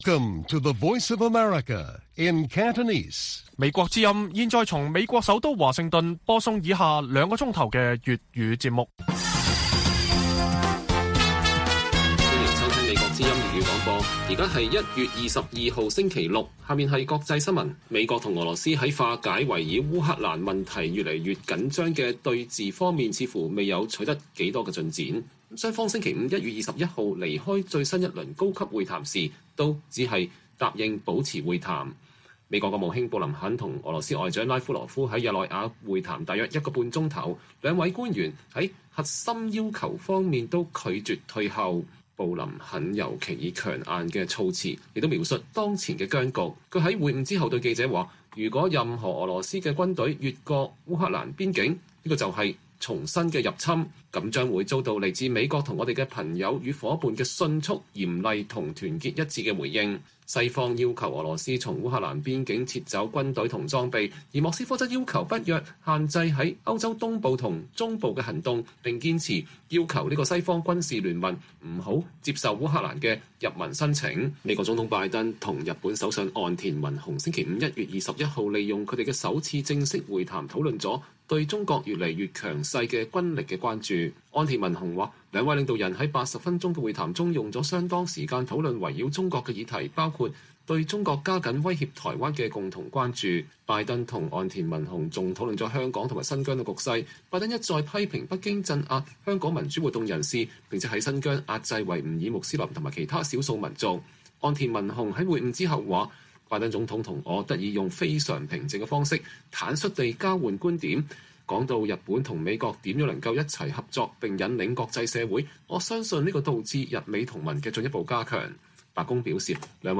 粵語新聞 晚上9-10點 : 中國撒幣數千億 換取在中亞國家影響力